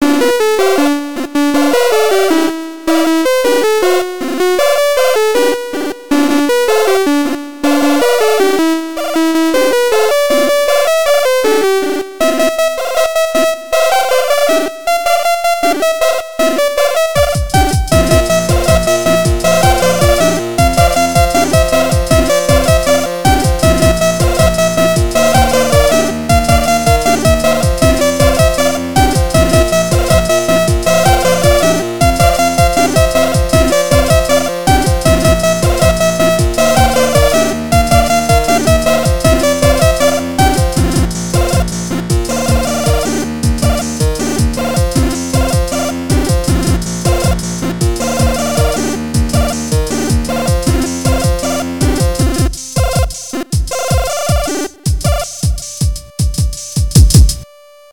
For the occasion I’ll link again a cheesy tune I wrote several years ago with Milky Tracker (that in the meantime was open sourced, yay;), a beautiful retro application for writing the Amiga-derived MOD and XM retro-tastic music format.